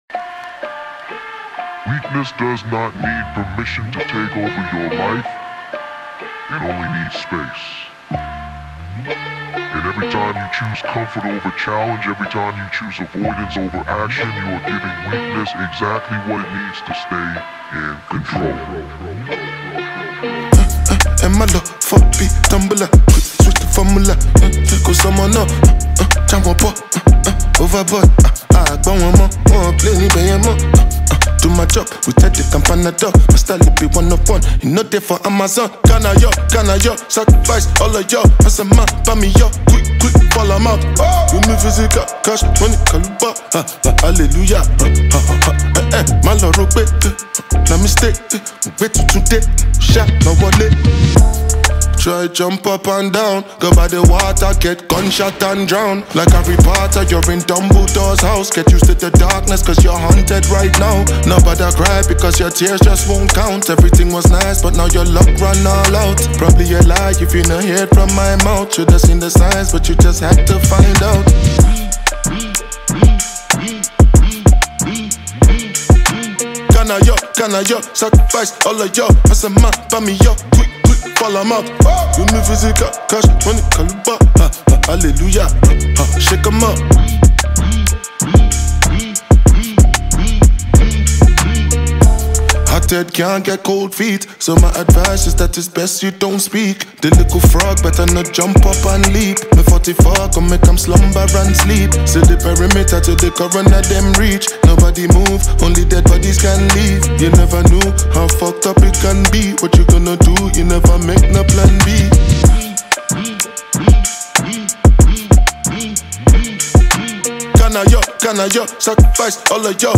a captivating anthem
bold, emotional